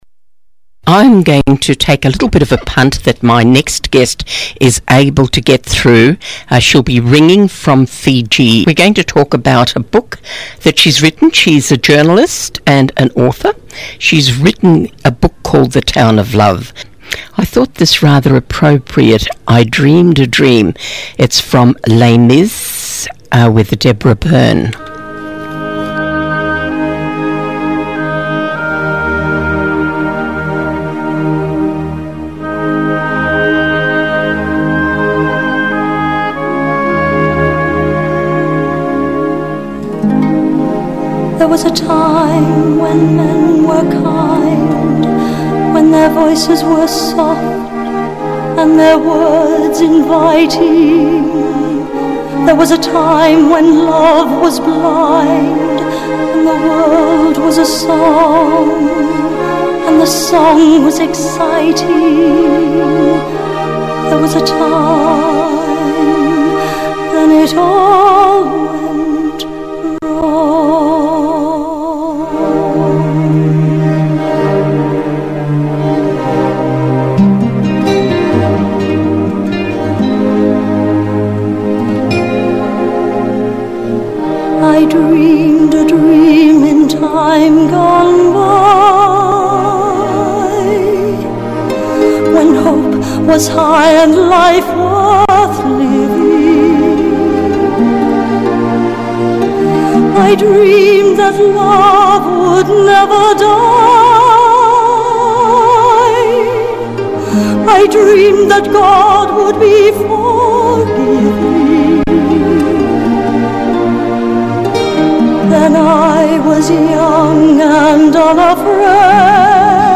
Podcast of Interview